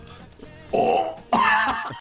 Big Smoke Burp